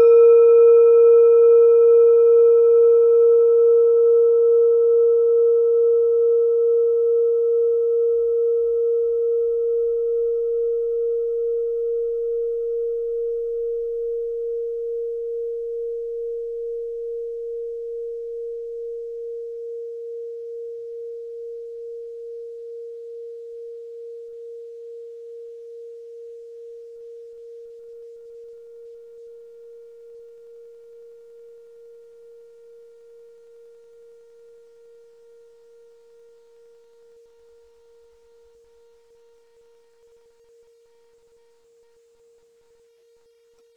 Kleine Klangschale Nr.31 Nepal, Planetentonschale: Mondknotenumlauf
(Ermittelt mit dem Minifilzklöppel)
Der Klang einer Klangschale besteht aus mehreren Teiltönen.
Die Klangschale hat bei 467.52 Hz einen Teilton mit einer
kleine-klangschale-31.wav